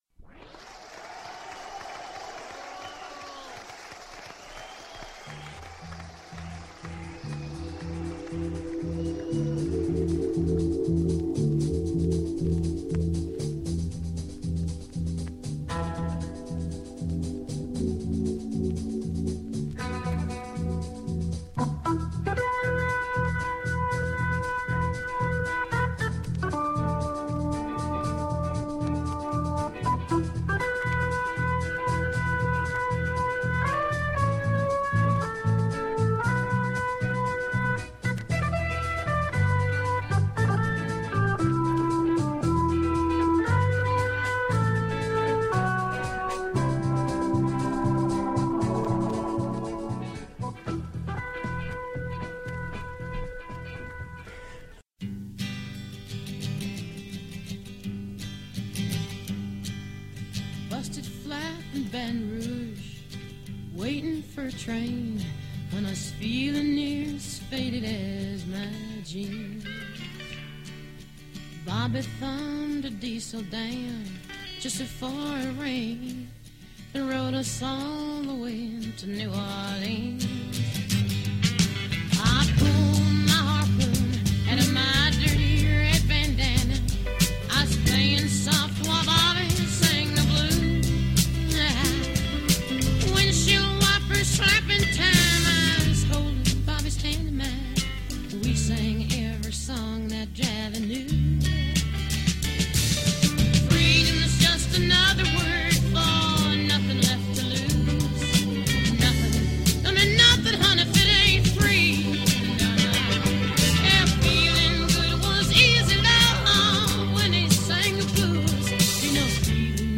Reprise of a 2012 interview